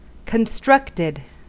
Ääntäminen
Ääntäminen US Tuntematon aksentti: IPA : /kənˈstɹʌktəd/ Haettu sana löytyi näillä lähdekielillä: englanti Käännös Adjektiivit 1. aufgebaut 2. konstruiert Constructed on sanan construct partisiipin perfekti.